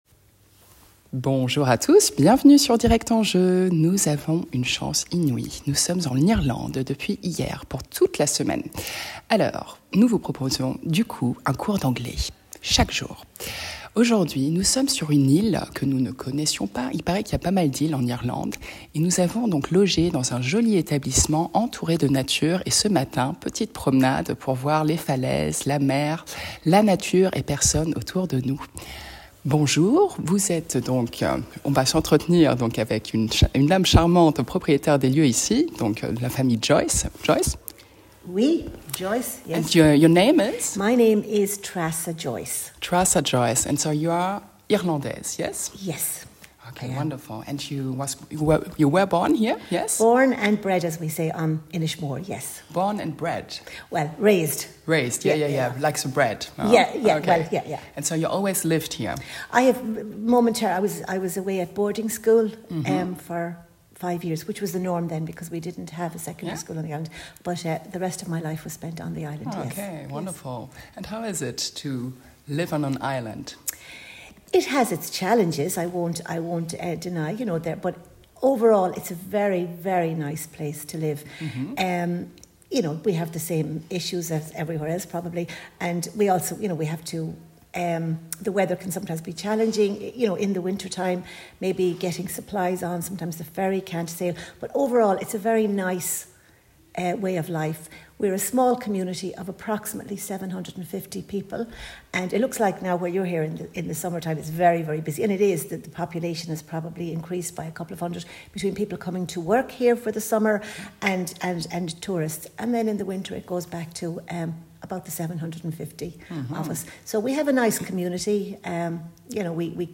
ITW en anglais